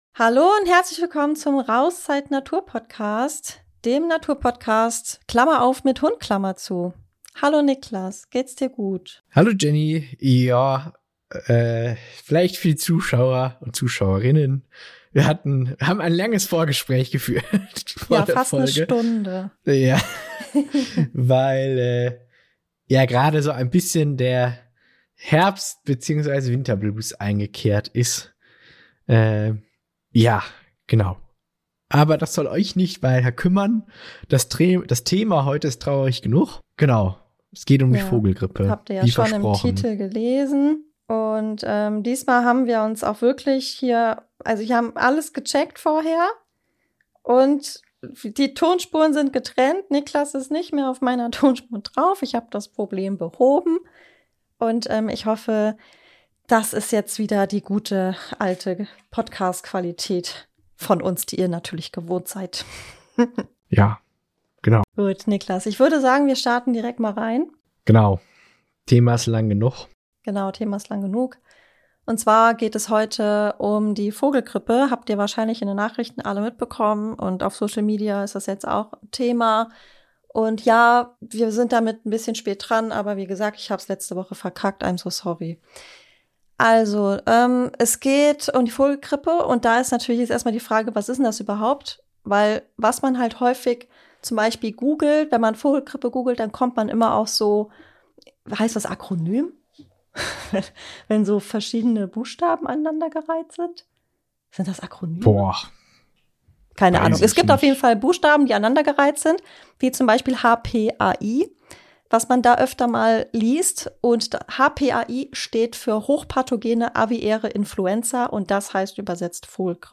Diesmal ist die Tonqualität wieder am Start – dafür aber eine ordentliche Portion Virenwissen, Zynismus, denn es wird etwas düster.